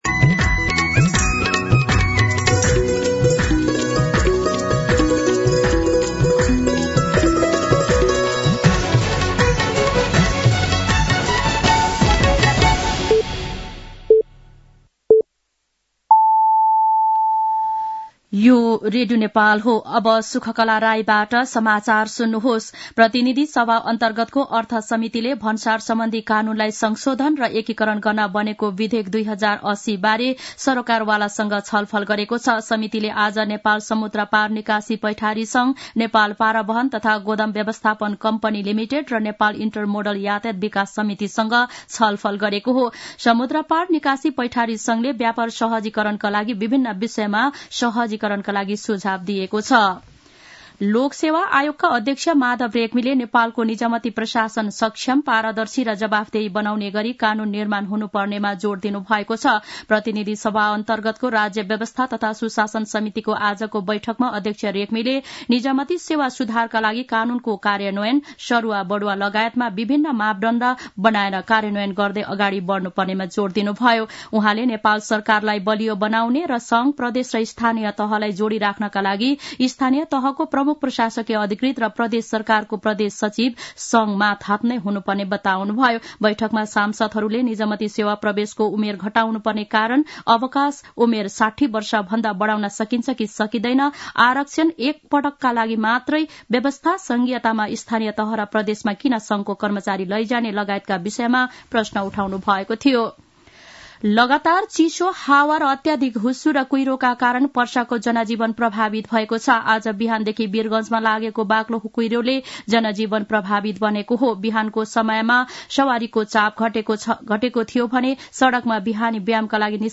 दिउँसो ४ बजेको नेपाली समाचार : २० पुष , २०८१